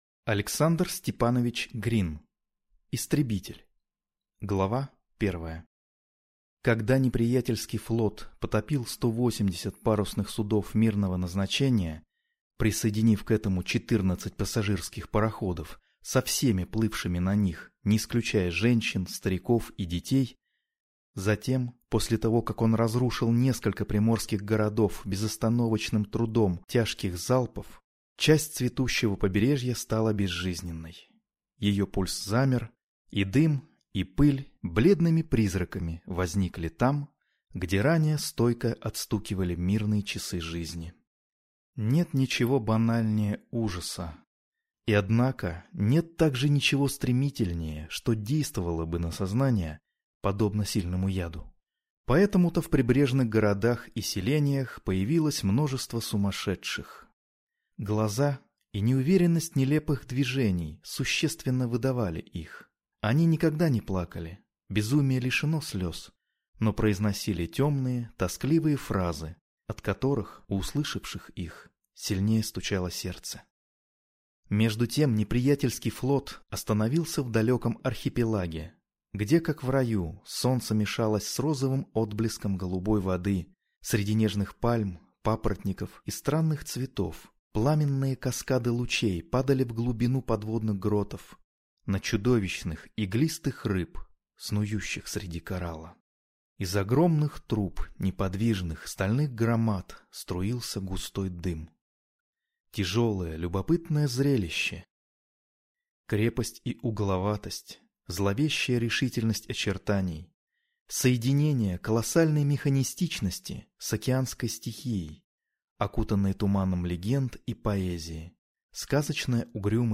Аудиокнига Истребитель | Библиотека аудиокниг